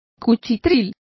Complete with pronunciation of the translation of hovel.